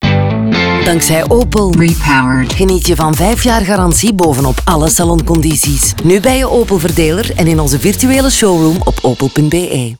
Radio Production: Sonhouse